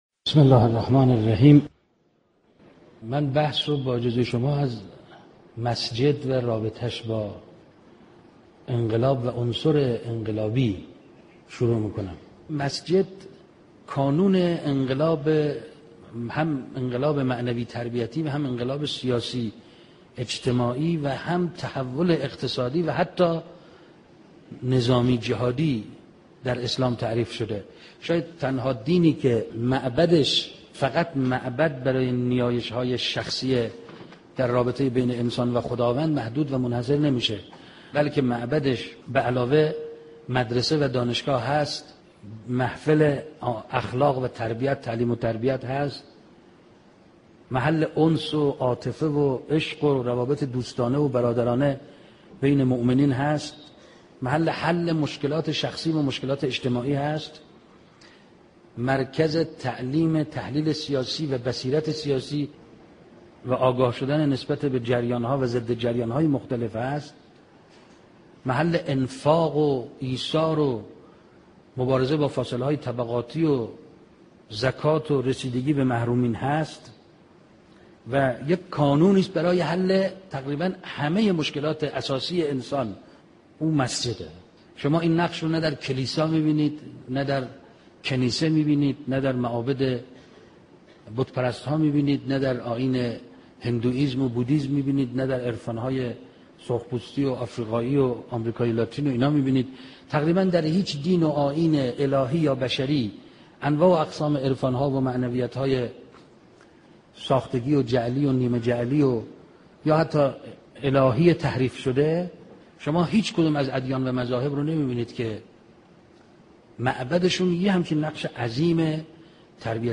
سخنرانی استاد حسن رحیم پور ازغدی درباره مسجد زنده و مسجد مرده